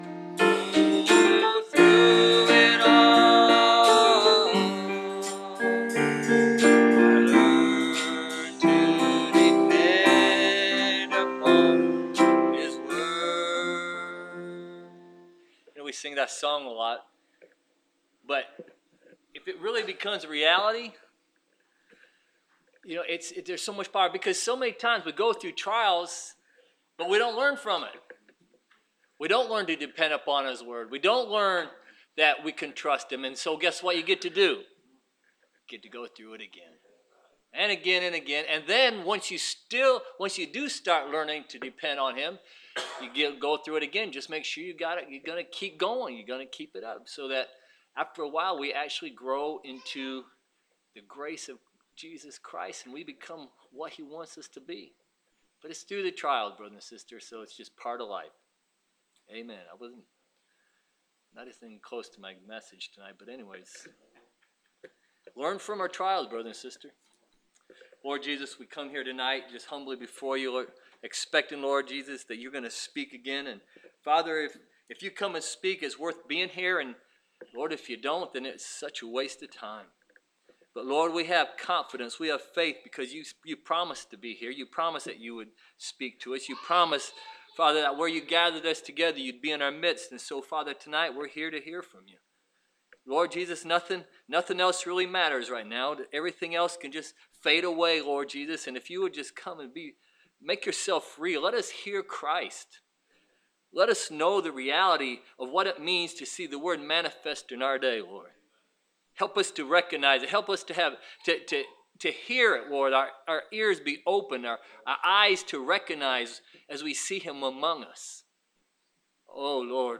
Preached 16 July 2015